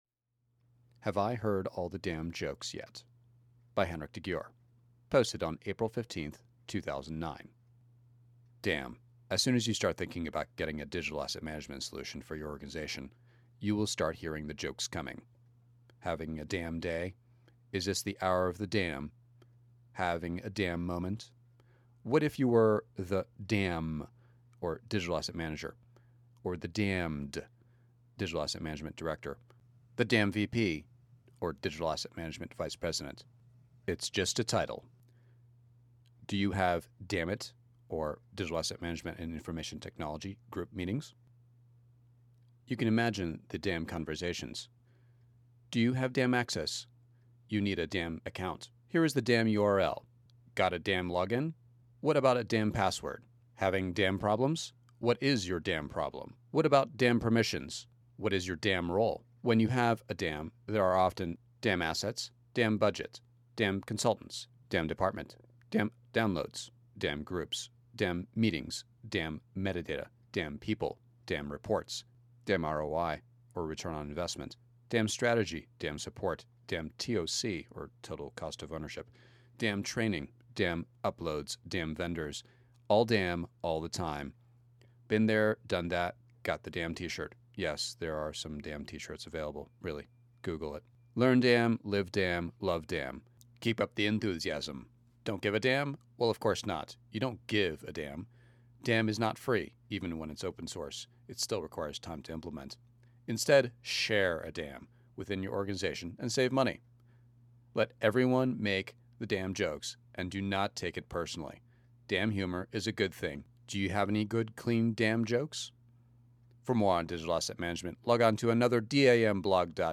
Here is the audio version of the blog post 'Have I heard all the DAM jokes yet?'